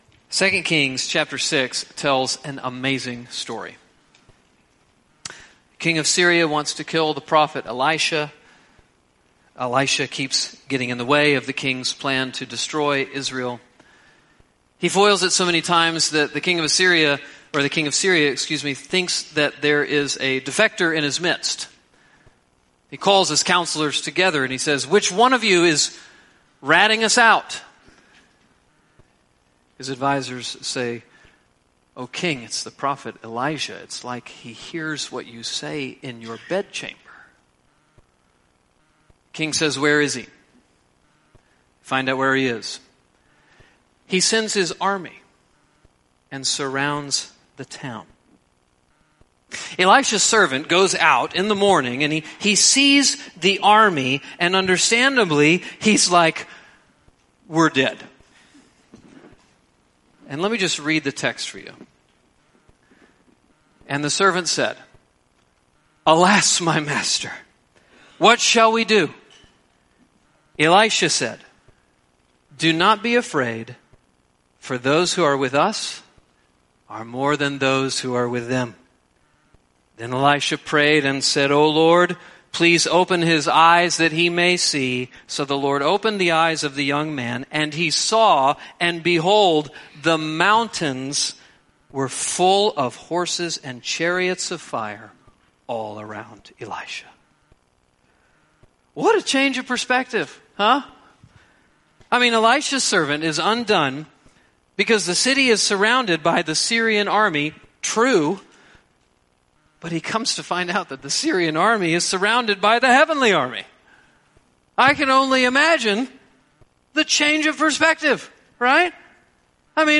Sermons preached at Redeeming Grace Church, Georgia VT.